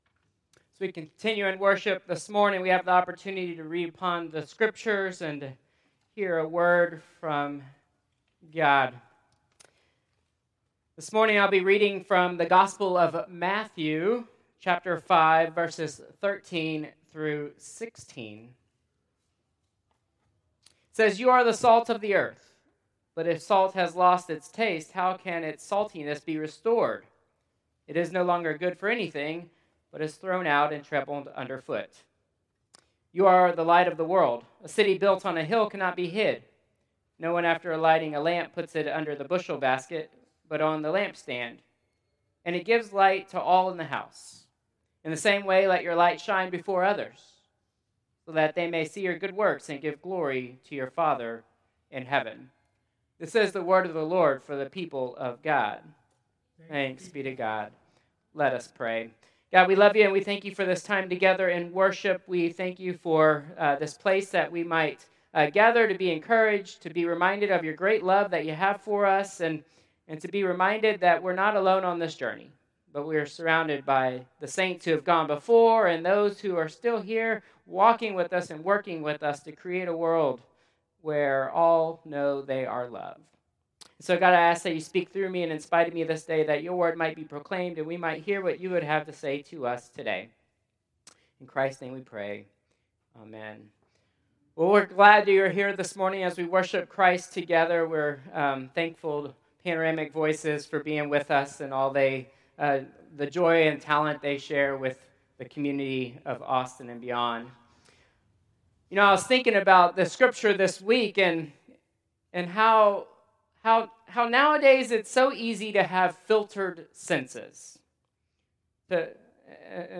Traditional Service 11/16/2025